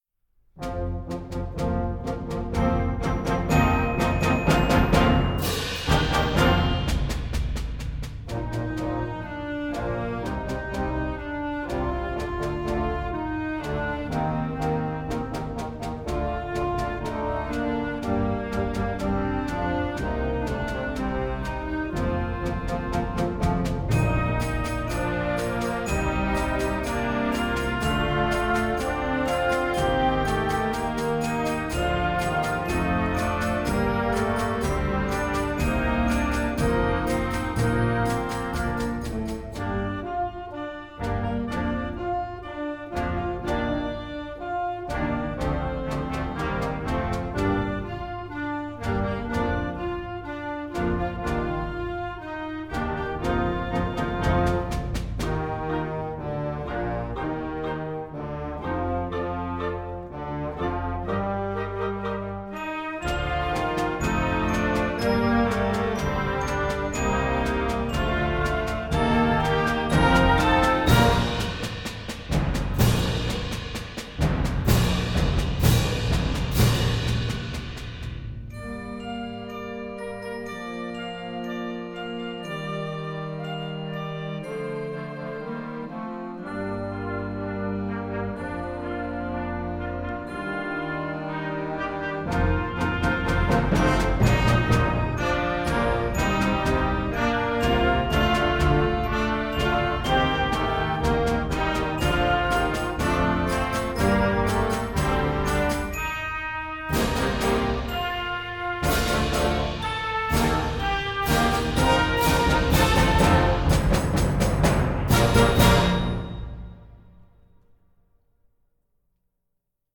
Gattung: Konzertwerk für flexibles Jugendblasorchester
Besetzung: Blasorchester